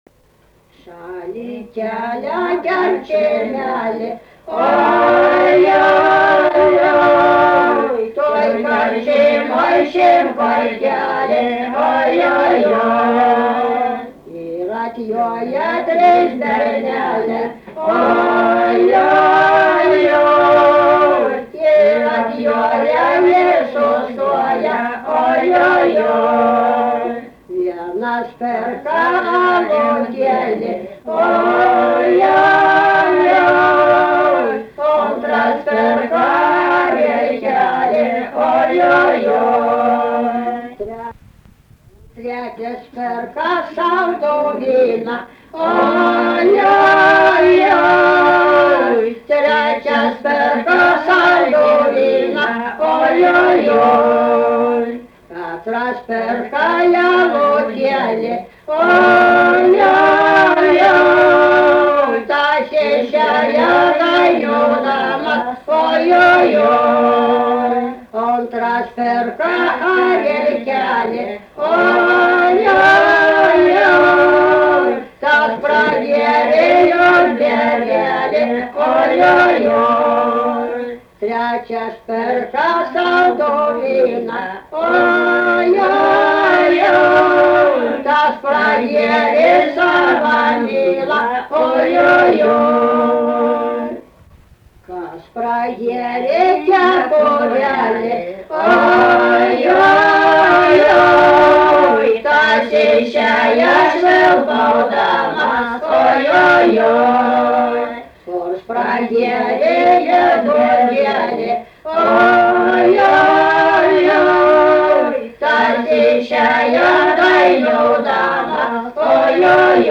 daina, vestuvių
Erdvinė aprėptis Luokė
Atlikimo pubūdis vokalinis
Pastabos 2-3 balsai